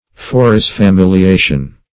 Forisfamiliation \Fo`ris*fa*mil`i*a"tion\, n. (Law)